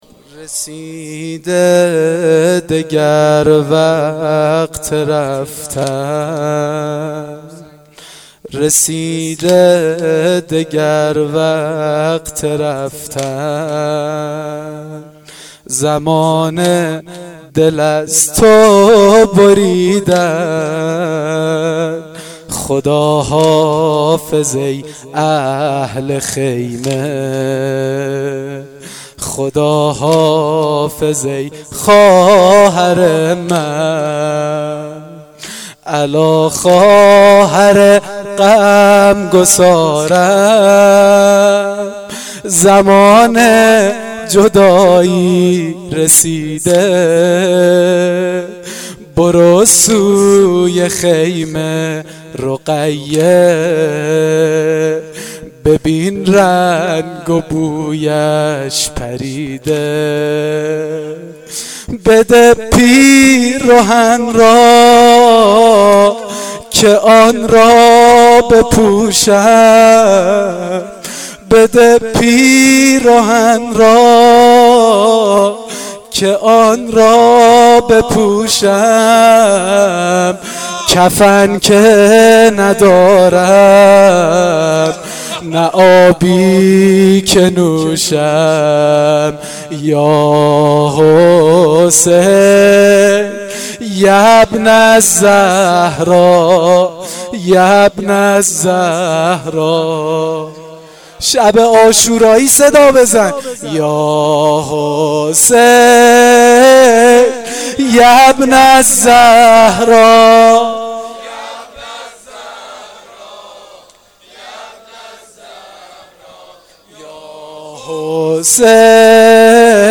واحدسنگین شب عاشورا محرم1393
Sangin-Shabe-10-moharram93.mp3